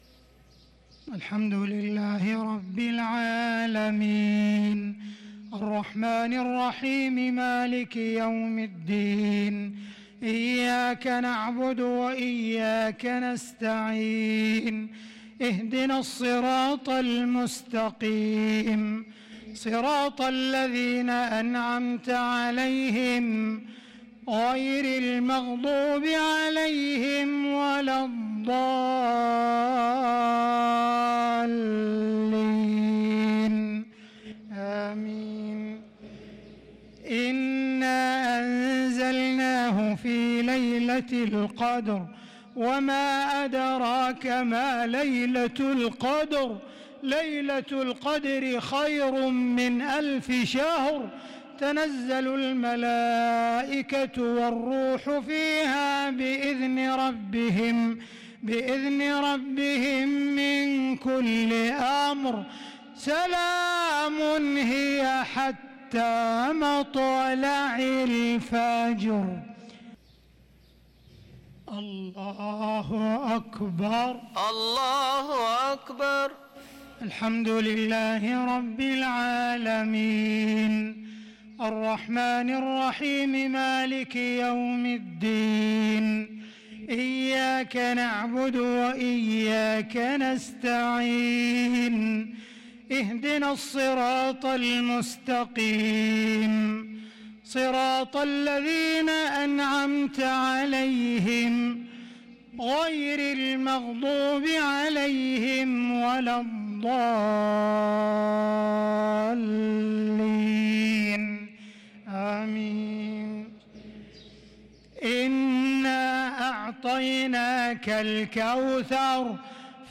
صلاة الجمعة 7-9-1443هـ سورتي القدر و الكوثر | Jumu'ah prayer Surat Al-Qadr and Al-Kawthar 8-4-2022 > 1443 🕋 > الفروض - تلاوات الحرمين